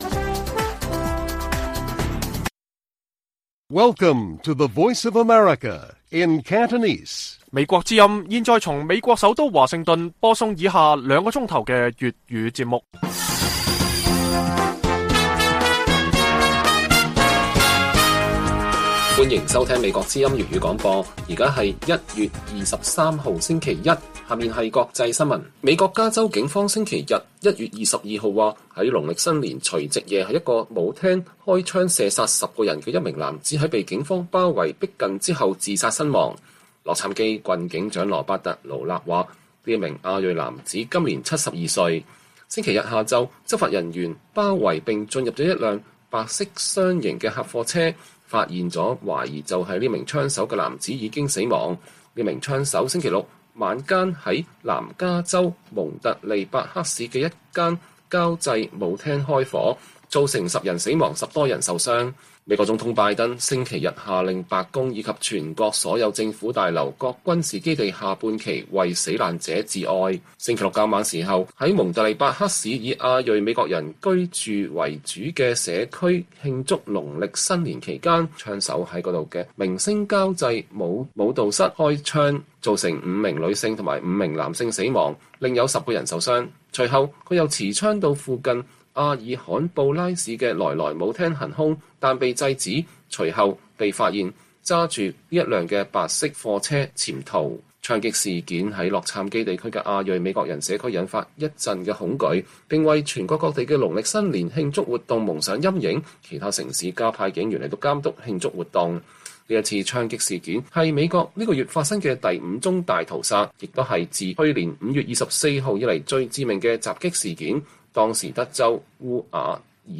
粵語新聞 晚上10-11點 : 人口拉警報 中國“未富先老”引發印度熱議